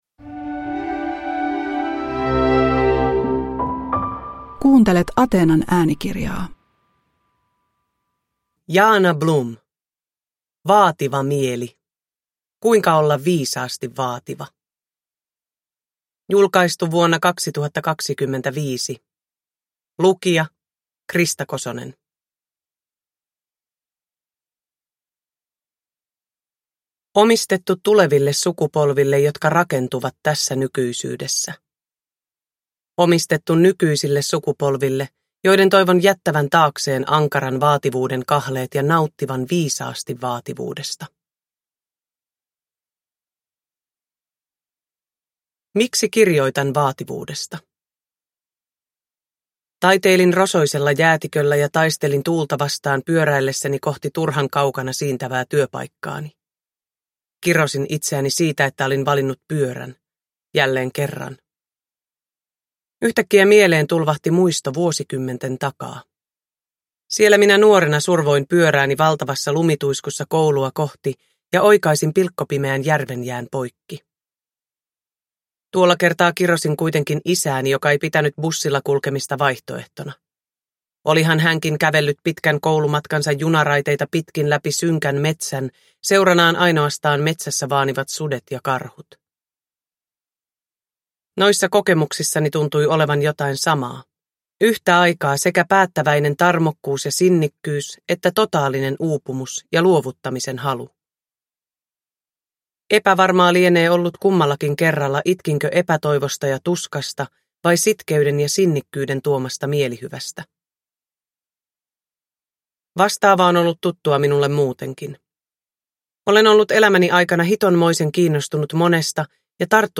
Vaativa mieli – Ljudbok
Uppläsare: Krista Kosonen